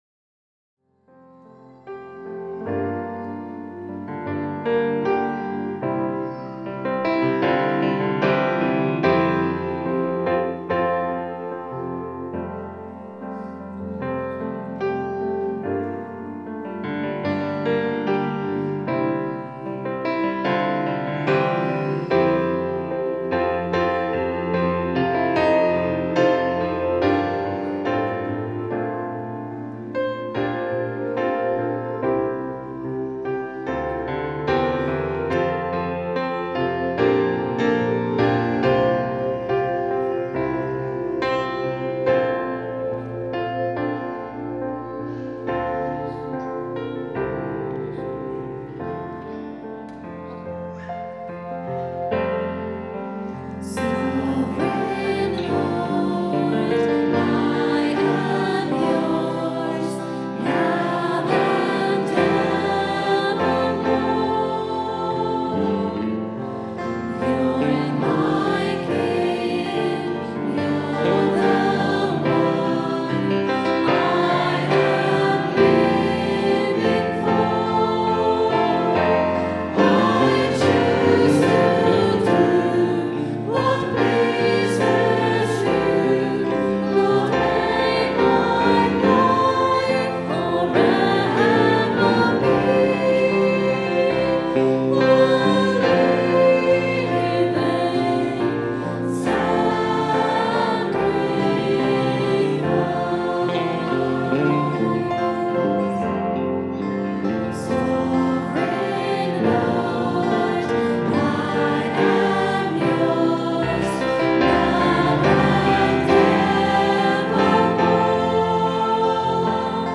Recorded on a Zoom H4 digital stereo recorder at 10am Mass Sunday 27th June 2010.